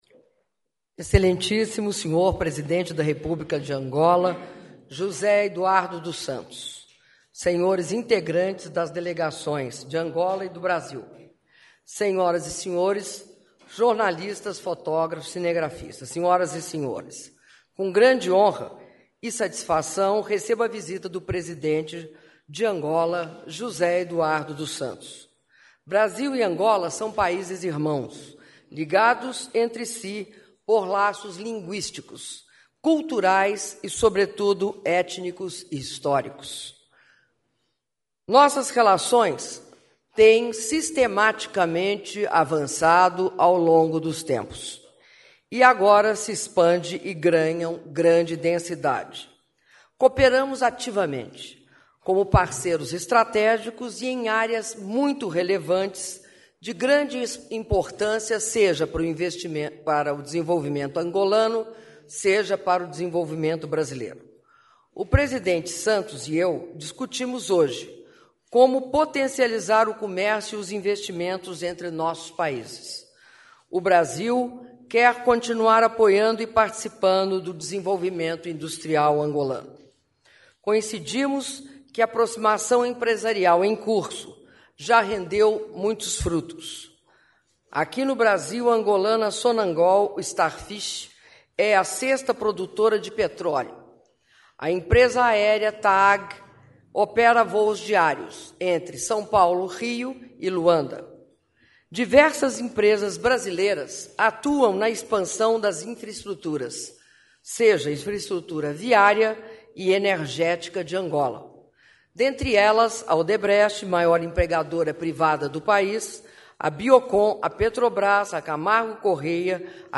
Áudio da declaração à imprensa da Presidenta da República, Dilma Rousseff, após encontro bilateral com o Presidente da República de Angola, José Eduardo dos Santos (07min07s)